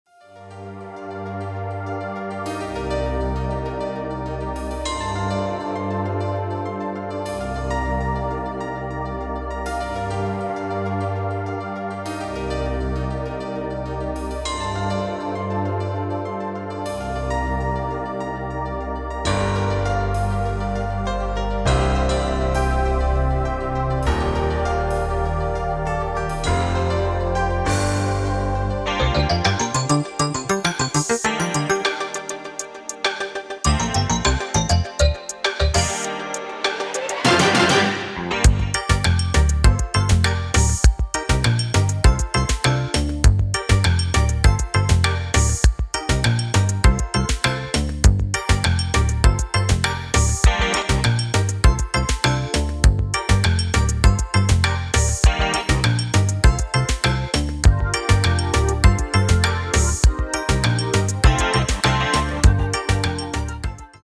middle eastern records flipped for the dancefloor